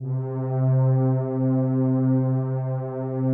PAD 47-3.wav